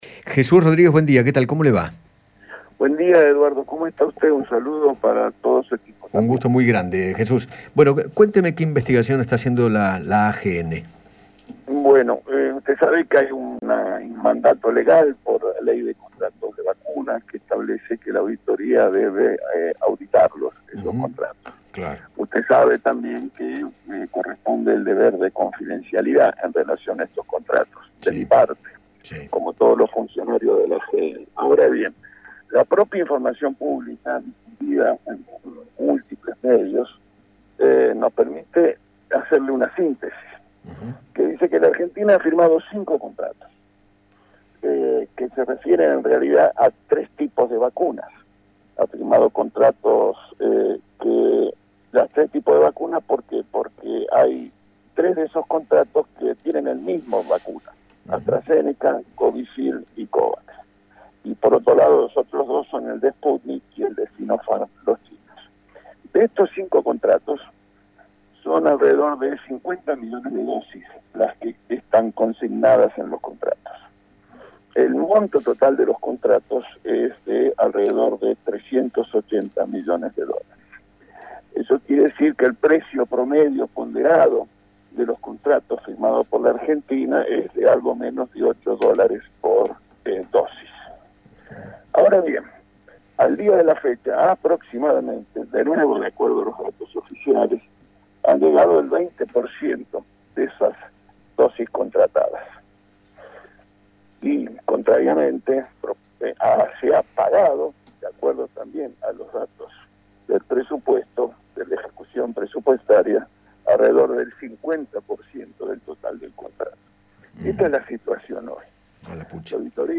Jesús Rodríguez, presidente de la Auditoría General de la Nación, conversó con Eduardo Feinmann sobre la investigación que comenzó debido a la baja llegada de vacunas contra el coronavirus.